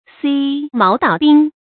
挦毛搗鬢 注音： ㄒㄧㄢˊ ㄇㄠˊ ㄉㄠˇ ㄅㄧㄣˋ 讀音讀法： 意思解釋： 扯頭發，打耳光。形容兇悍。